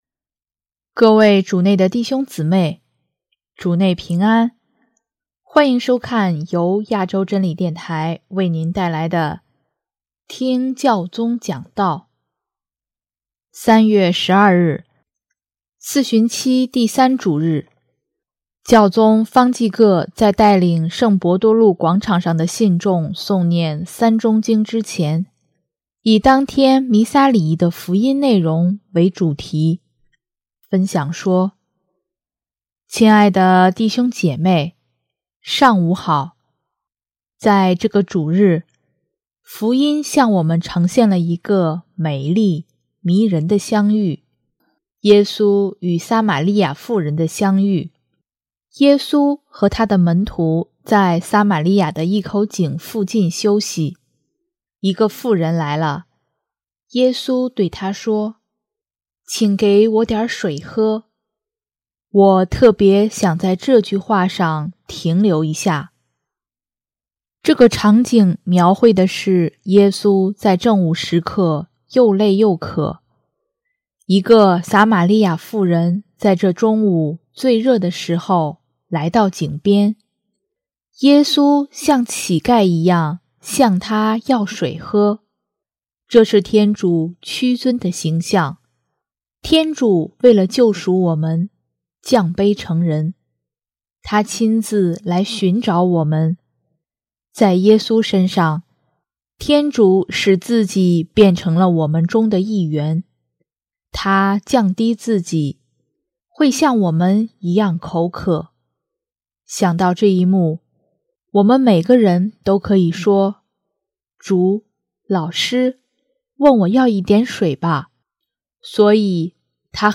3月12日，四旬期第三主日，教宗方济各在带领圣伯多禄广场上的信众诵念《三钟经》之前，以当天弥撒礼仪的福音内容为主题，分享说：